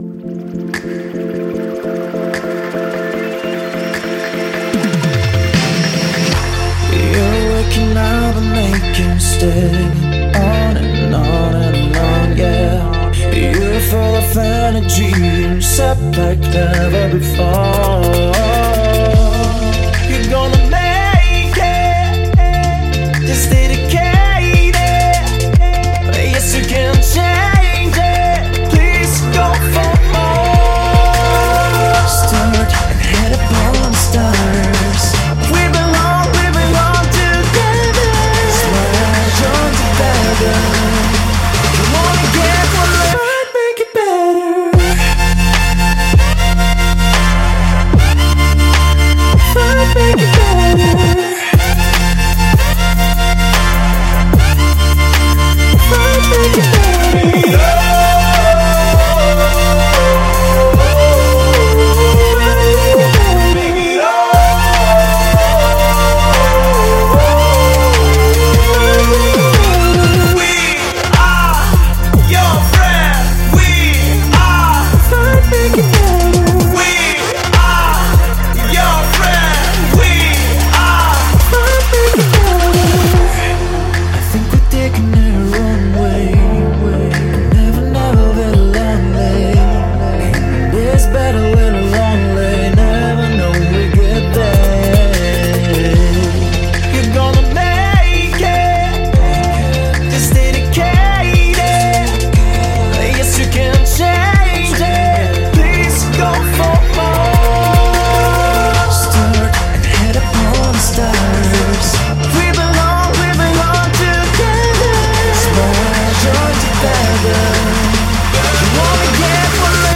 Жанр: Жанры / Поп-музыка